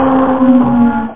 1 channel
TurntableStop.mp3